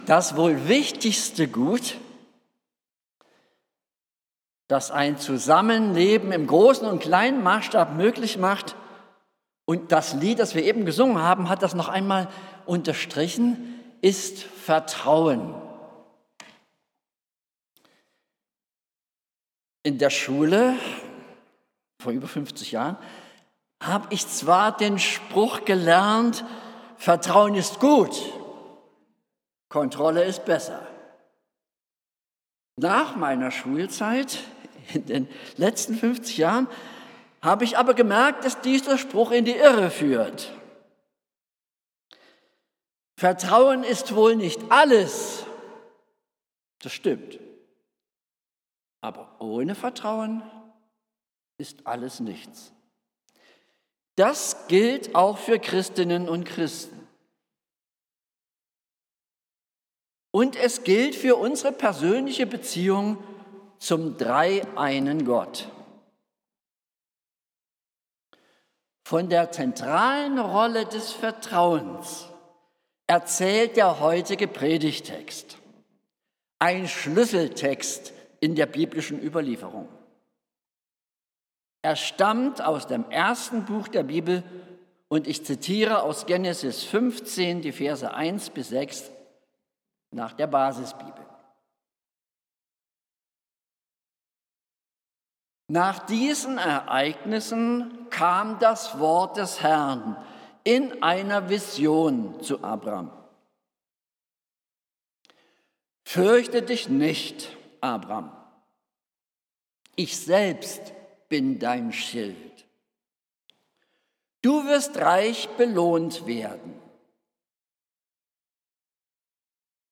Predigten | Bethel-Gemeinde Berlin Friedrichshain